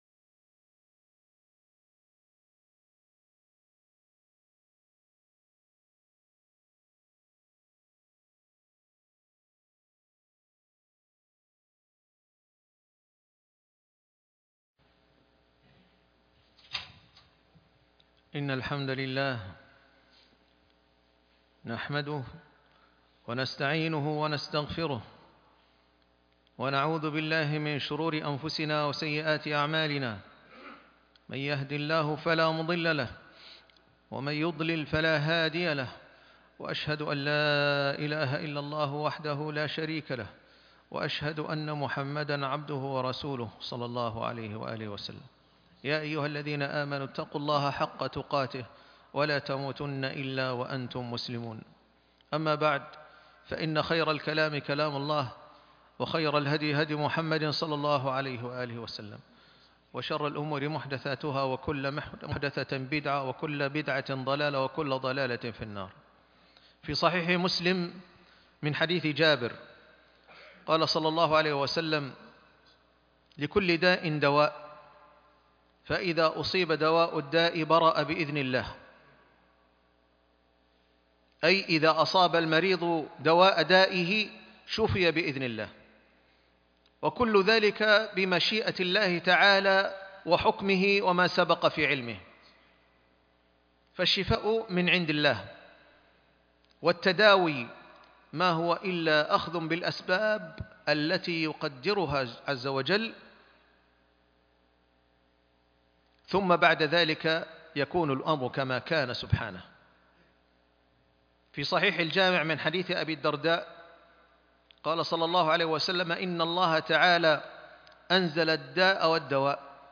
اللقاح (خطب الجمعة